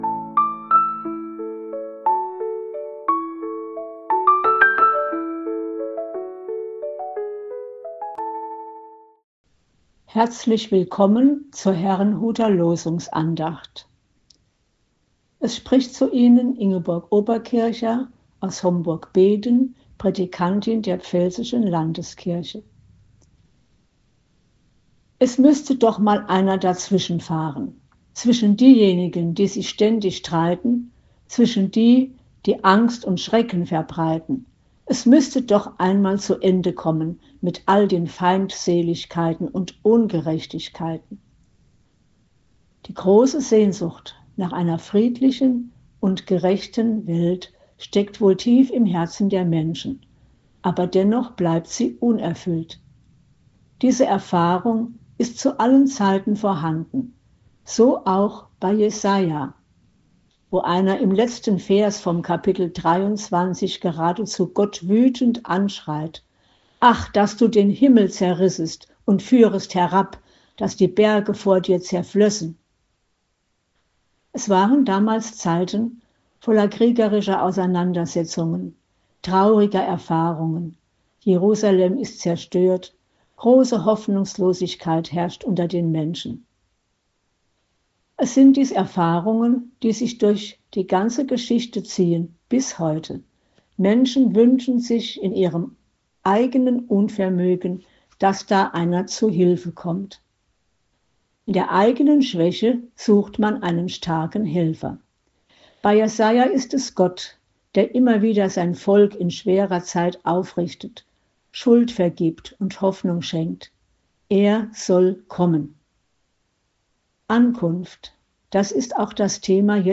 Losungsandacht für Freitag, 12.12.2025
Losungsandachten